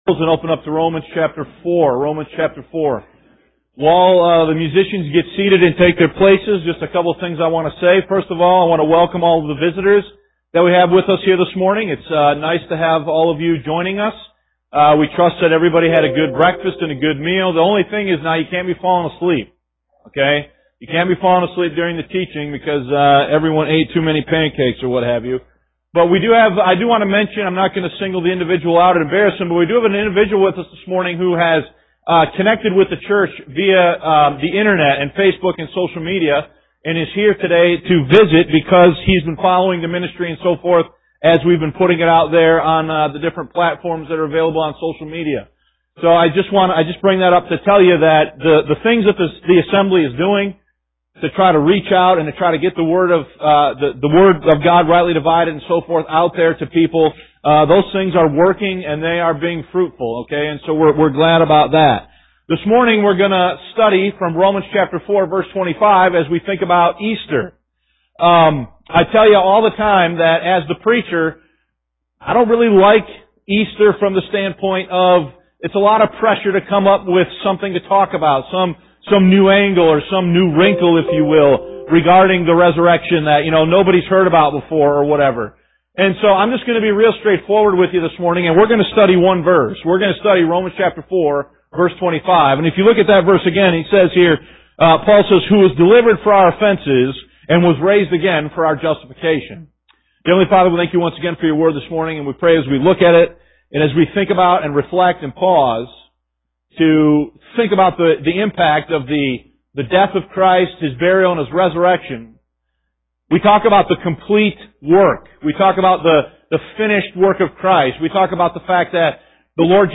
Sermon Notes Romans 4 Verse 25 Christ's Finished Work